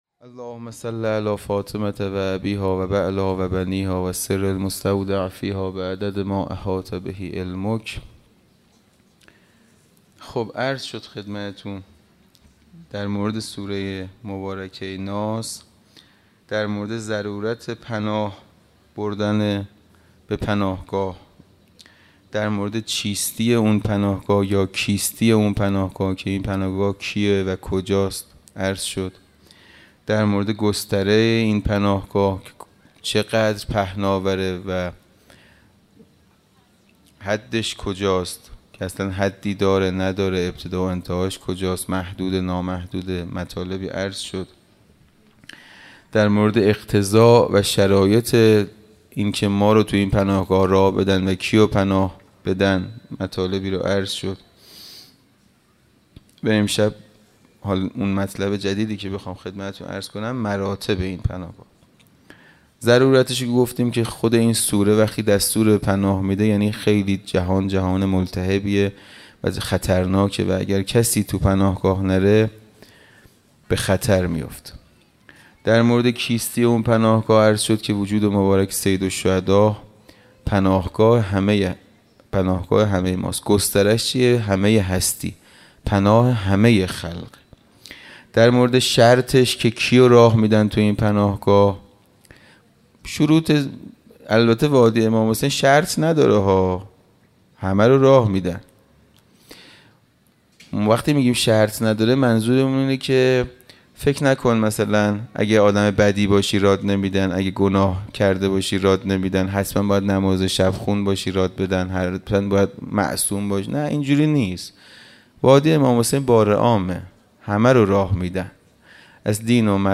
خیمه گاه - حسینیه کربلا - شب تاسوعا- سخنرانی